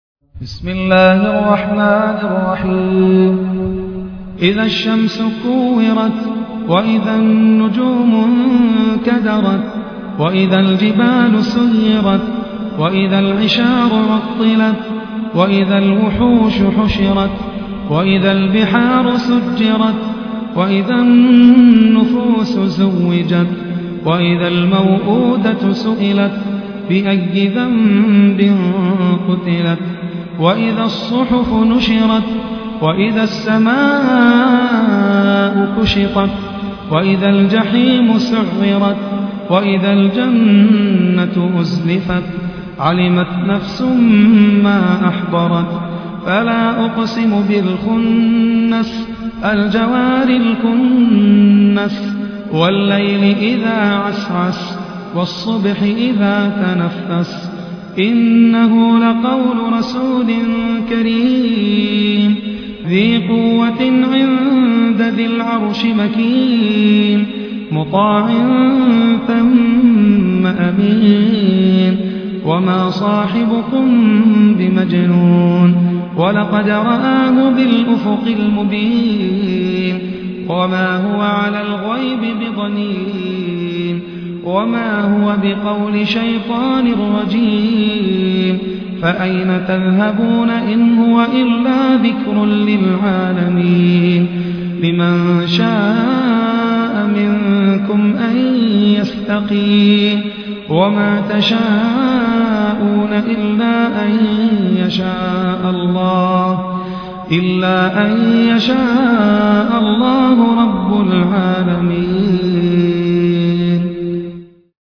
المصحف المرتل - حفص عن عاصم